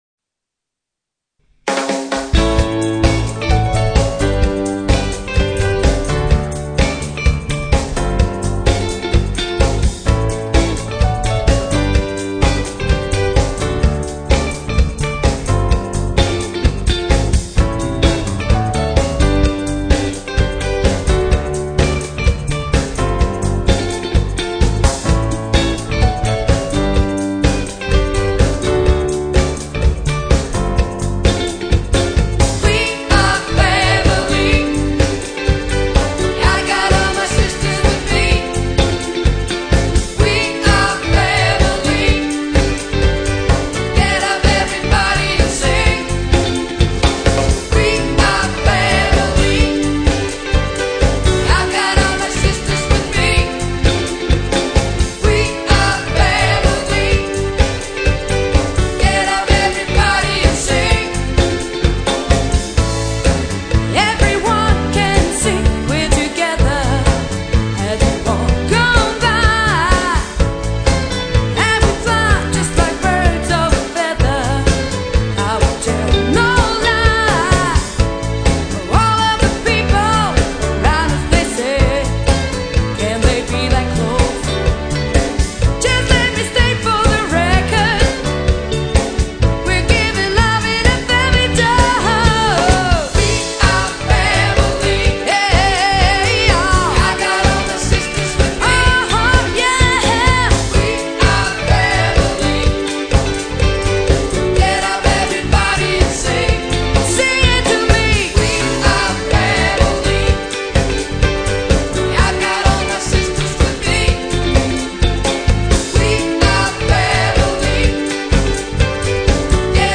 Kopiband i topklasse - de største hits fra 80'erne og frem.
vokal
guitar
keys
trommer